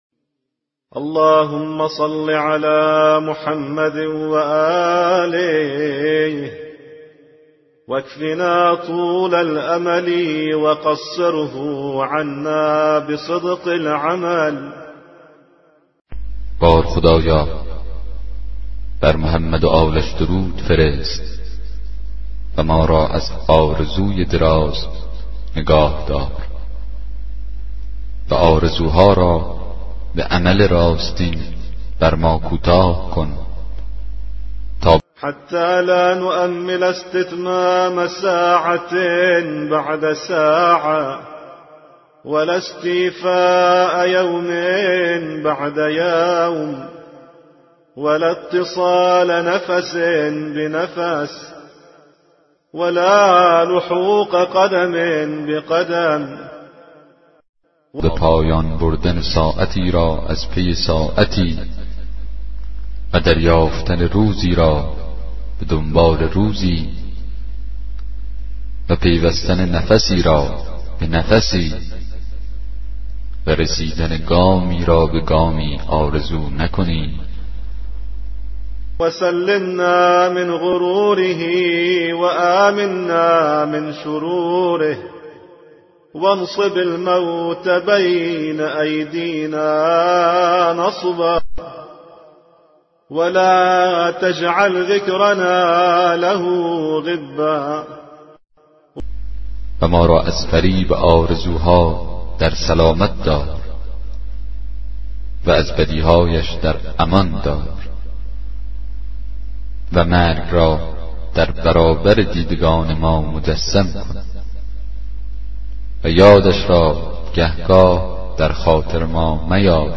کتاب صوتی دعای 40 صحیفه سجادیه